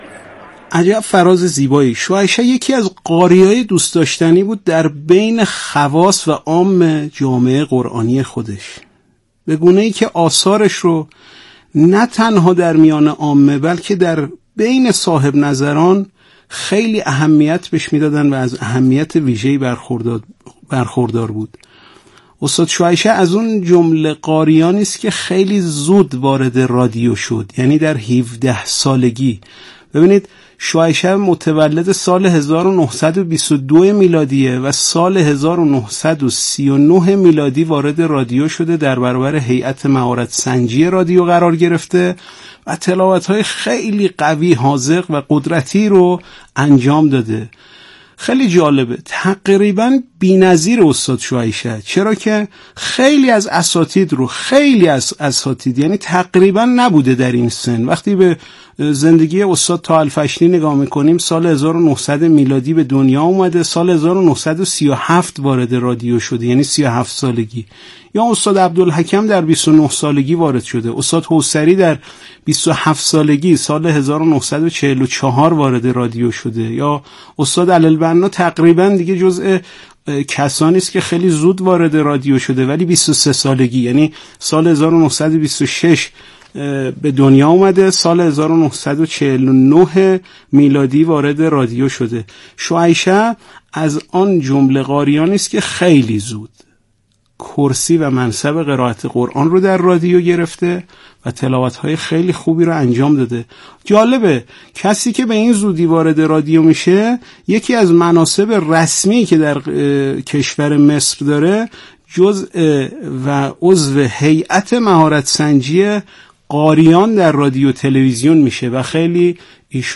گروه فعالیت‌های قرآنی ــ ابوالعینین شعیشع دارای صوت بسیار قوی، زنگ‌دار و مساحت صوتی کامل بوده و تحریر‌های فوق‌العاده ریز و دندانه‌دار داشته است. در مجموع وی تلاوتی حزین، خاشع و در عین حال بسیار محکم دارد.
یادآور می‌شود این تحلیل در برنامه «اکسیر» از شبکه رادیویی قرآن پخش شد.